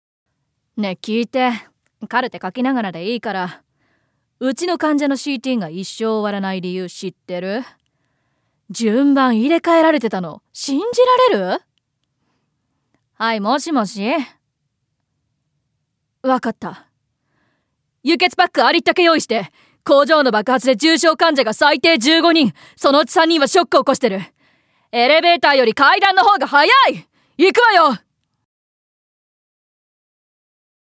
◆名乗り+うっかり屋なキツネの神様◆
◆明るい少年◆
◆女医◆